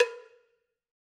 Cowbell1_Hit_v4_rr1_Sum.wav